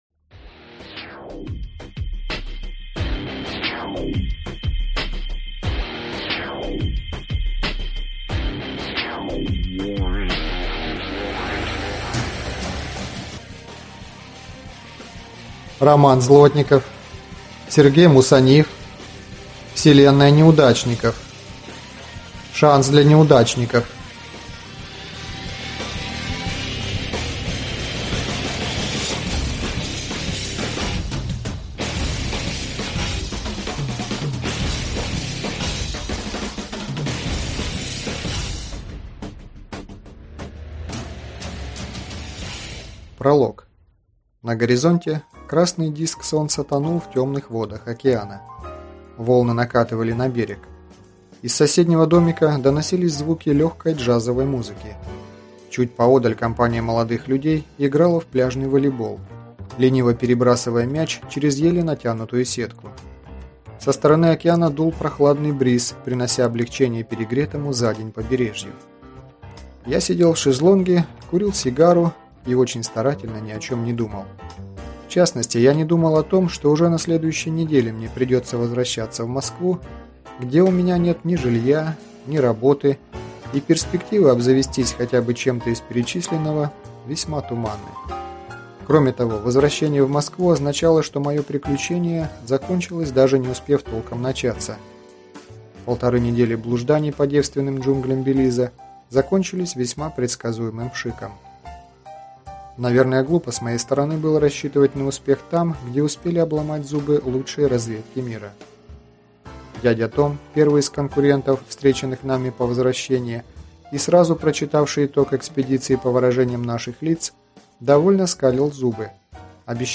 Аудиокнига Шанс для неудачников | Библиотека аудиокниг